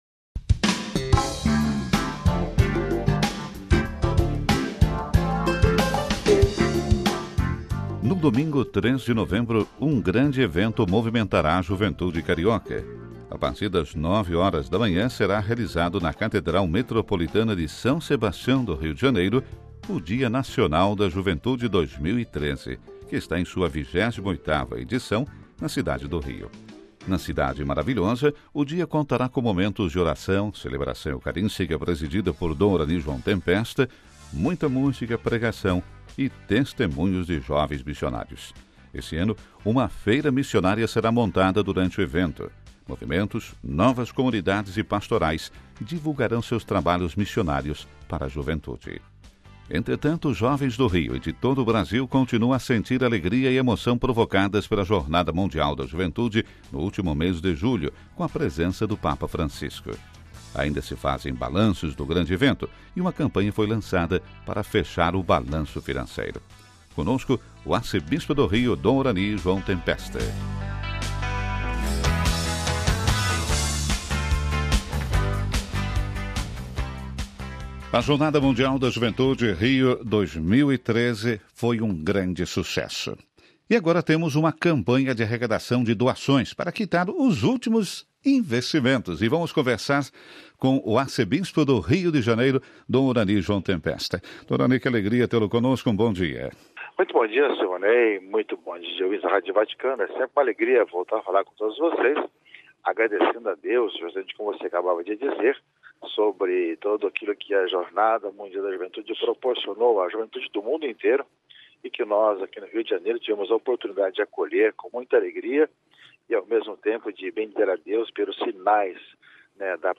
Ainda se fazem balanços do grande evento, e uma campanha foi lançada para fechar o balanço financeiro. Conosco o Arcebispo do Rio, Dom Orani João Tempesta.